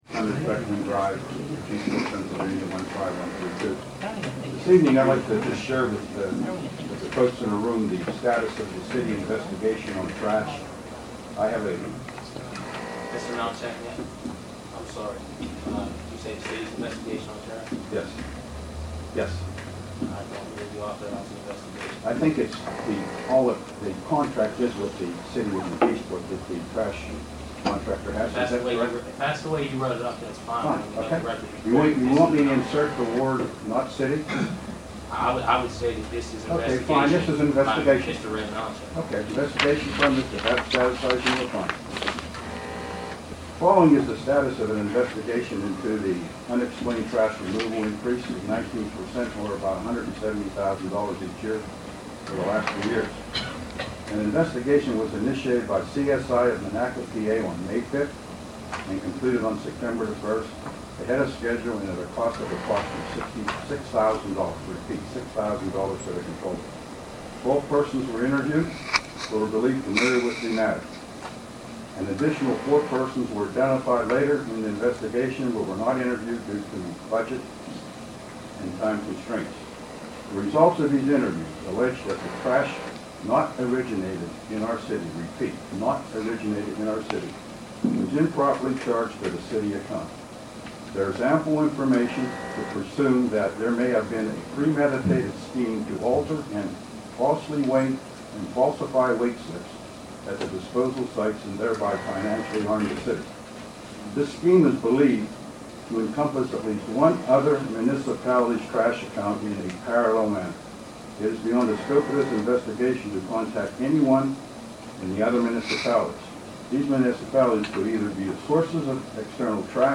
Malinchak made his announcement during a public comment period at Wednesday night's city council meeting.